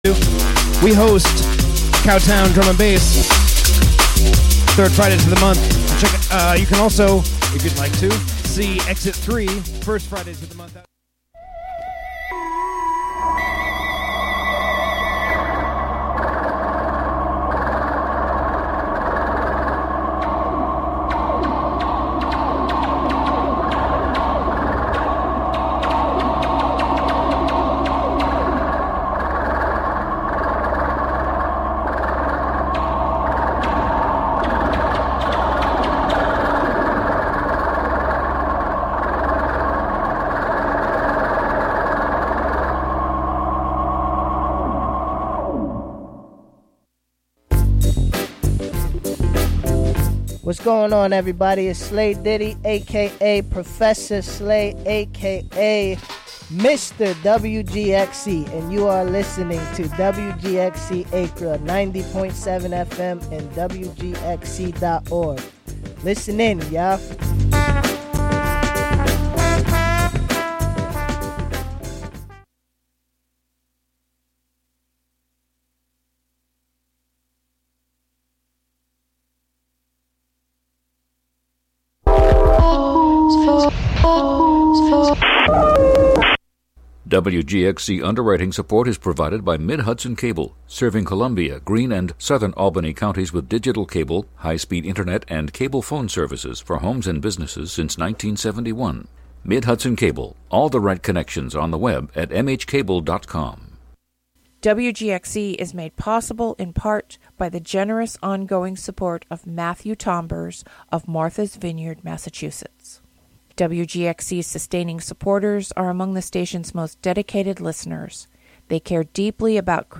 This weekly program of non-entropic thought sounds like what happens if you don't go to the dentist. Broadcast live from somewhere in Hudson, New York on WGXC (90.7-FM) and Standing Wave Radio (1620-AM).